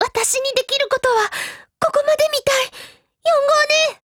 贡献 ） 协议：Copyright，其他分类： 分类:少女前线:UMP9 、 分类:语音 您不可以覆盖此文件。
UMP9Mod_RETREAT_JP.wav